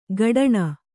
♪ gaḍaṇa